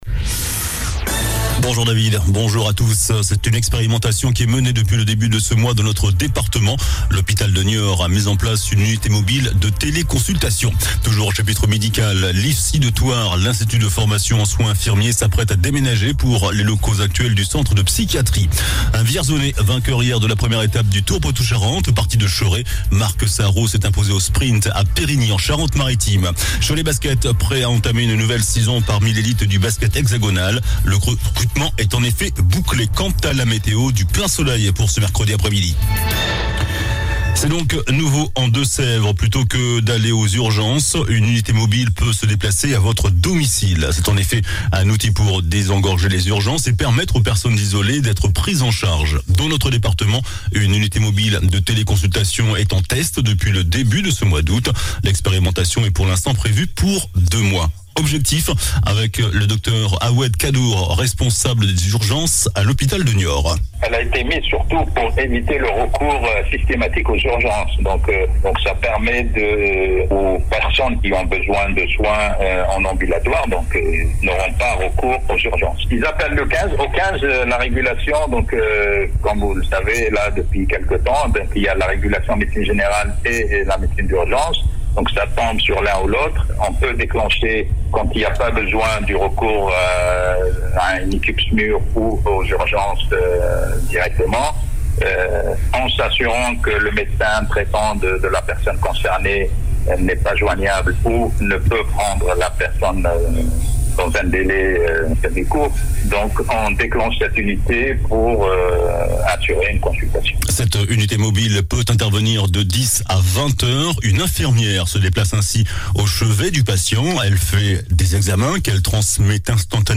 JOURNAL DU MERCREDI 24 AOÛT